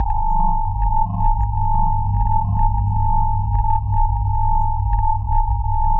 科幻无人机 " 无人机04
描述：科幻无人机，用于室内或室外房间的音调，气氛，外星人的声音，恐怖/期待的场景.
Tag: 外来 大气压 无人驾驶飞机 音响 恐怖 roomtones SCI